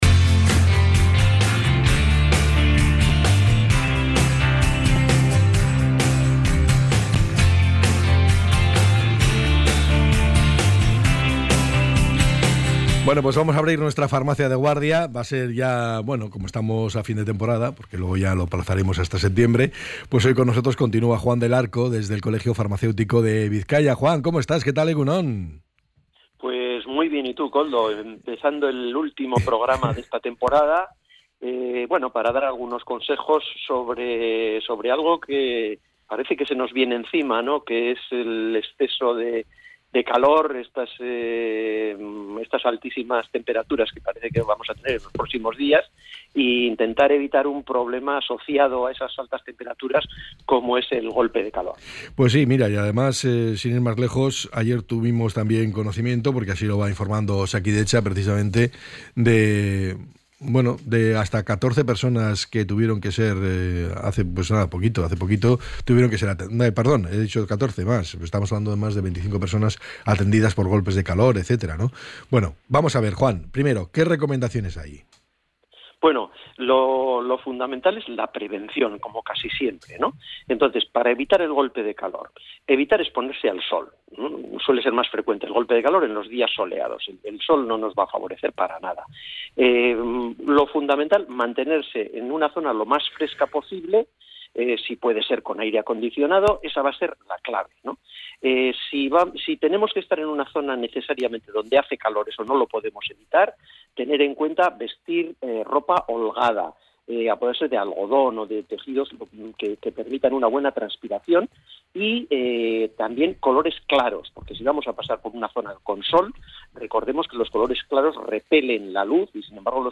A lo largo de la conversación, el farmacéutico ha explicado las claves para prevenir el golpe de calor y ha detallado los síntomas y medidas a tomar en caso de emergencia.